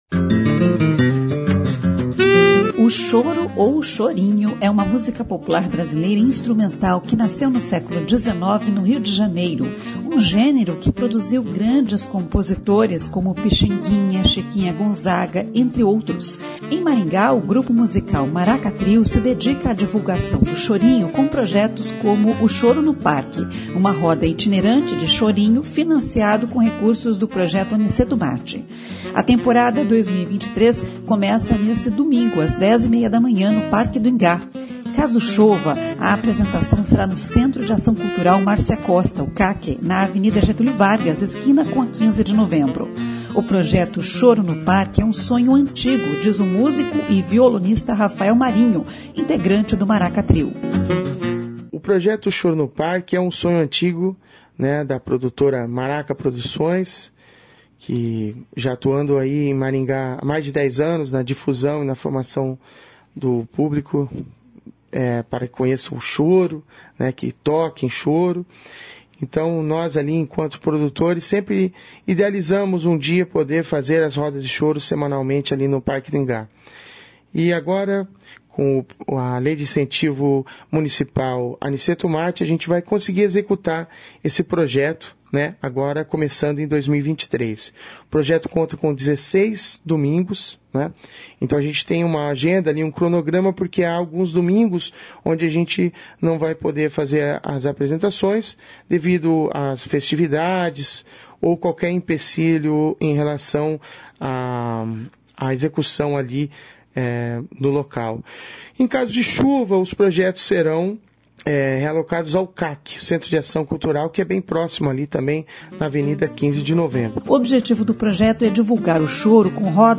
No repertório, clássicos do choro e também músicas autorais do Maraka Trio, como a canção Imune que sonoriza esta reportagem.